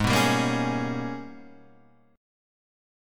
AbmM11 chord